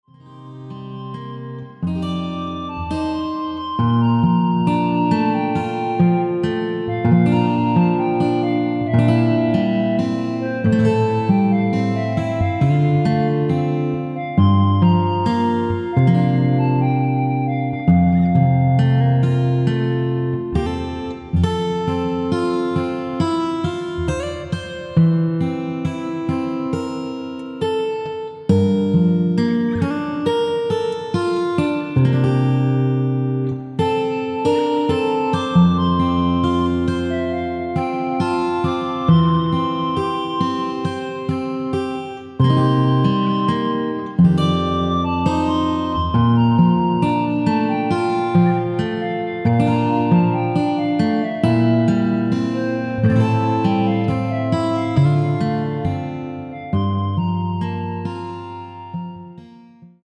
멜로디 포함된 MR입니다.
앞부분 “니가 어느날 ~ 오늘로 마지막이구나” 까지 없으며 간주도 4마디로 편곡 하였습니다.
앞부분30초, 뒷부분30초씩 편집해서 올려 드리고 있습니다.
중간에 음이 끈어지고 다시 나오는 이유는
축가 MR